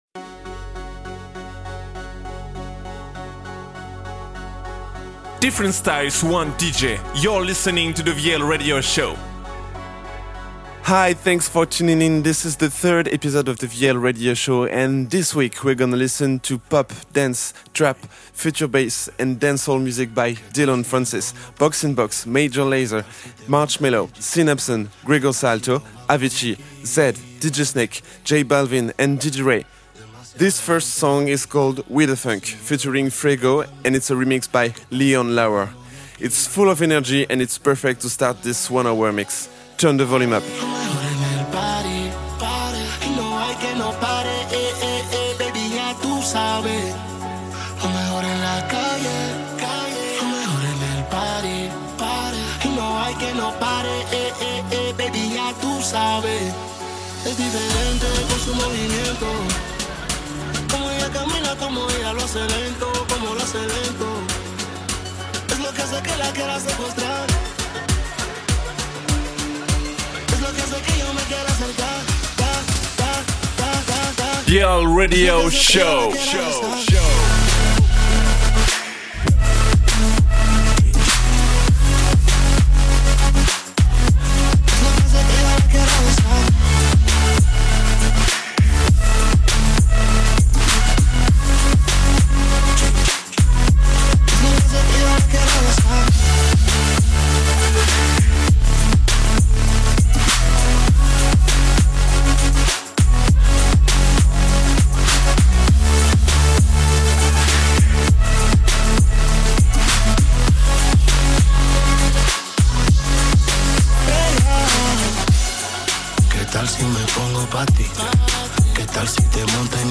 Future bass, dancehall, pop, dance & trap DJ mix.